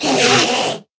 scream2.ogg